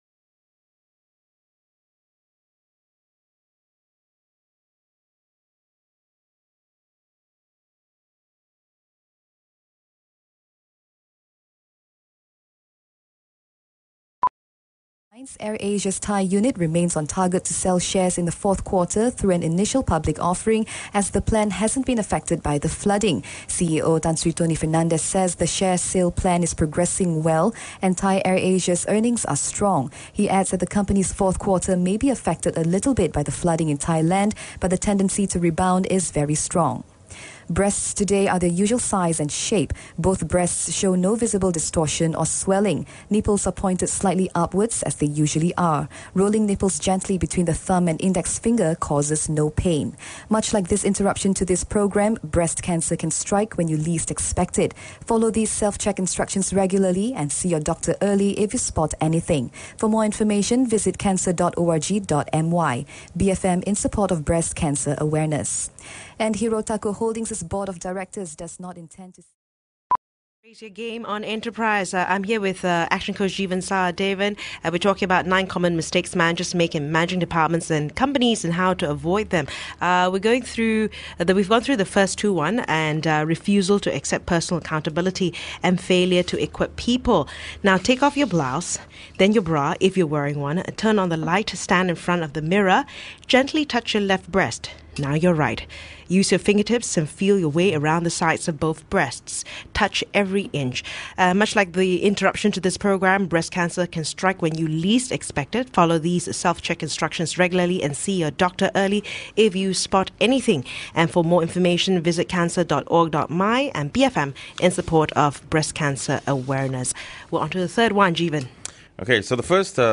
They interrupted their regular business news with breast cancer awareness messages – but did it by incorporating those messages seamlessly into their news reports, delivered by the newscasters themselves. Read in exactly the same style: